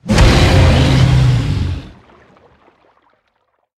Sfx_creature_snowstalker_death_swim_01.ogg